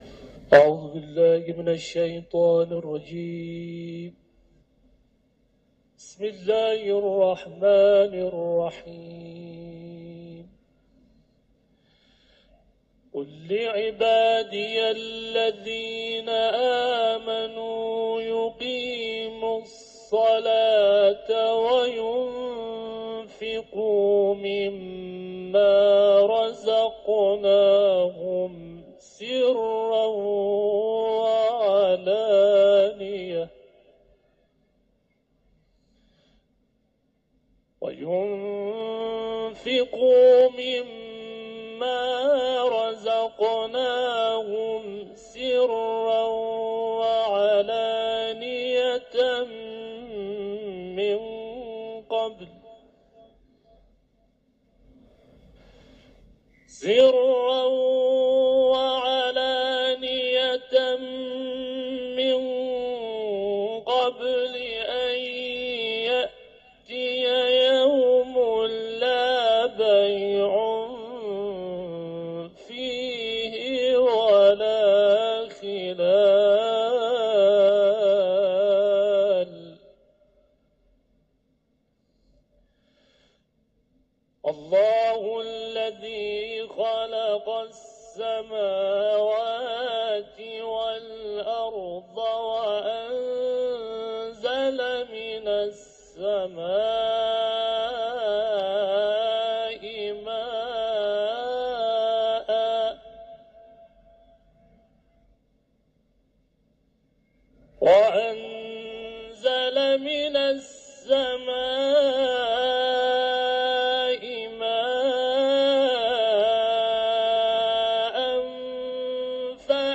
تلاوت‌ سوره‌ «ابراهیم»